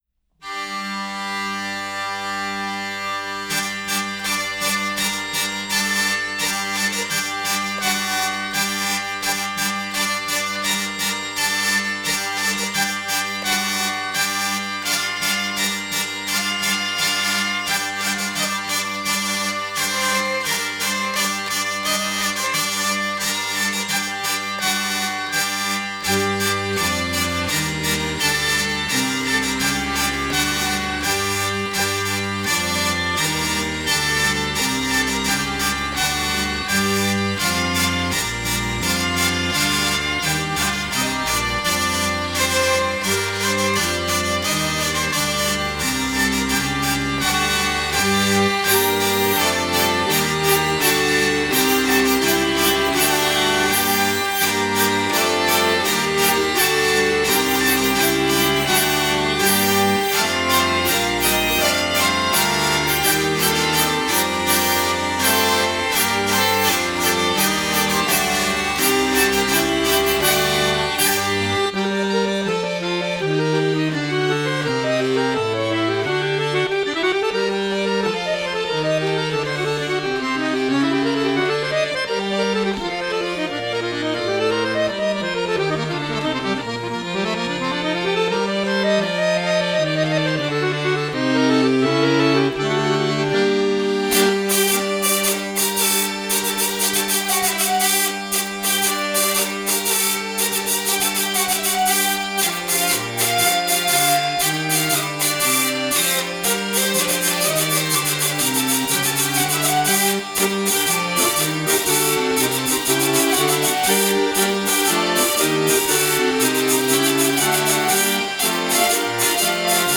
par Les Alwati et la Mère Folle dans Traditionnel Comtois